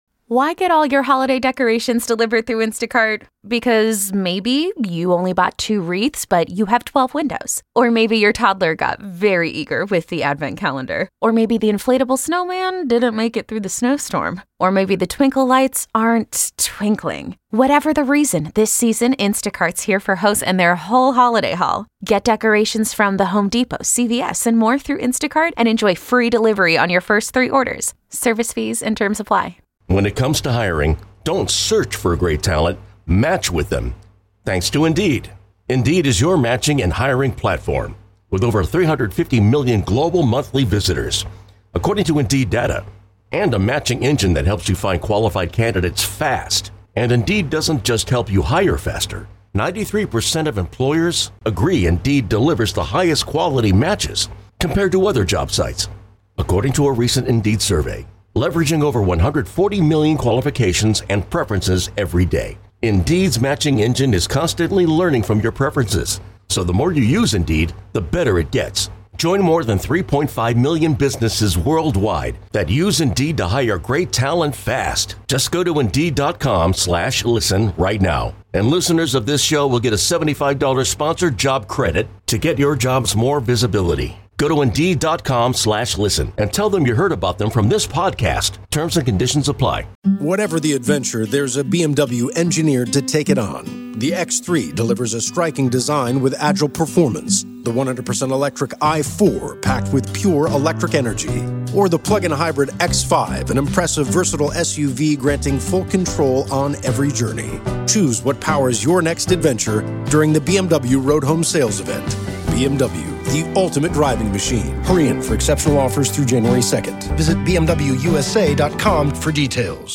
Engage with scripture readings, heartfelt devotionals, and collective prayers that draw you into the heart of God's l…